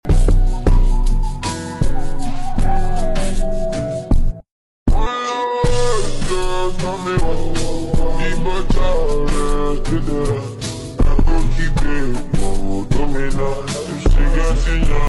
Calm sound ❤ sound effects free download